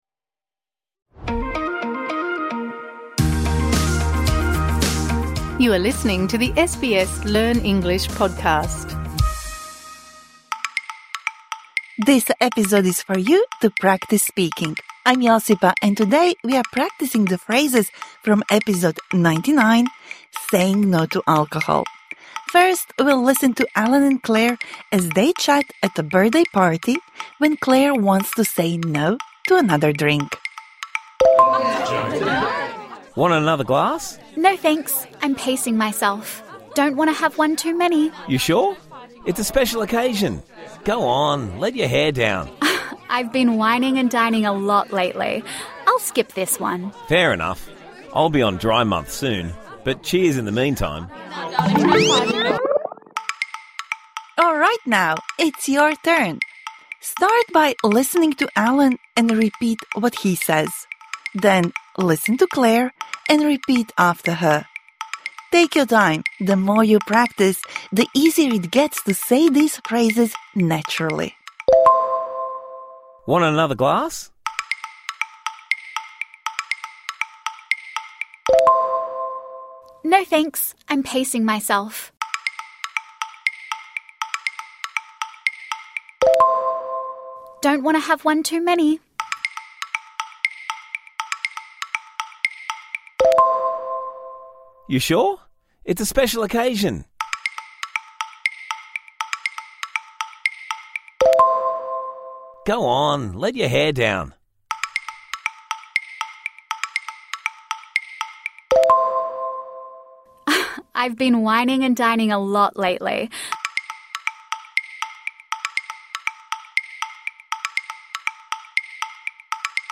تدرّب على التحدّث من خلال حوار الحلقة ٩٩: كيف تقول "لا" للكحول. هذه الحلقة الإضافية تساعدك على تحسين طلاقتك في التحدث بالإنجليزية وتذكّر العبارات الجديدة التي تعلّمتها.